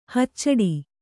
♪ haccaḍi